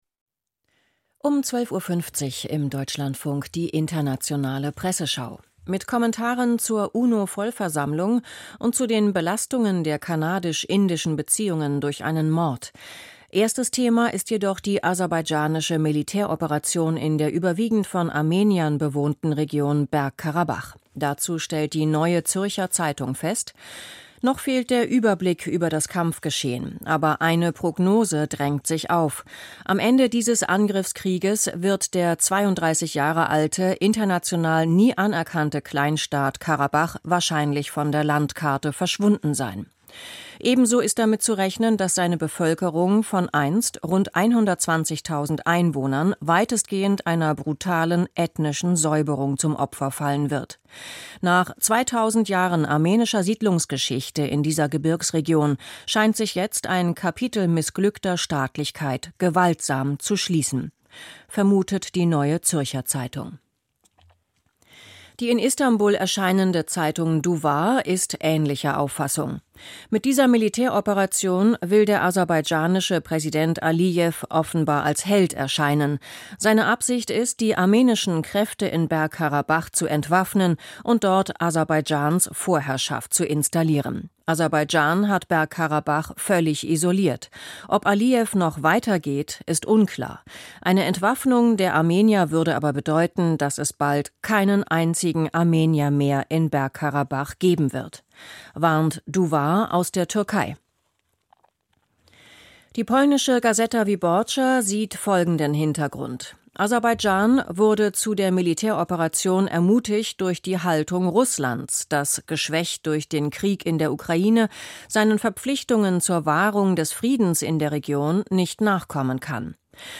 Presseschau. 21.